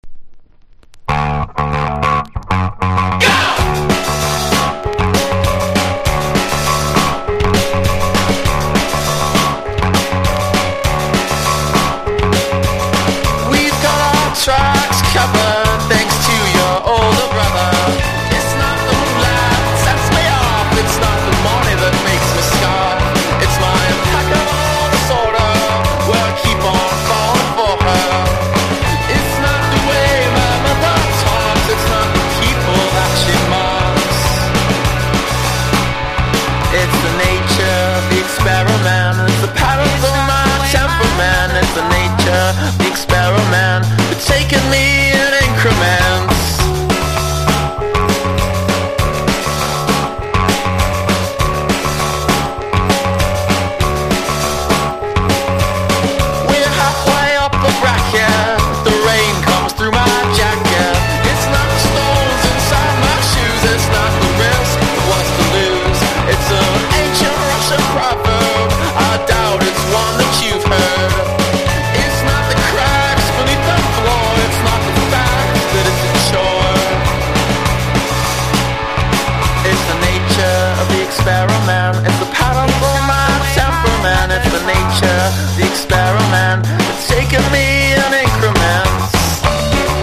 1. 00S ROCK >
INDIE DANCE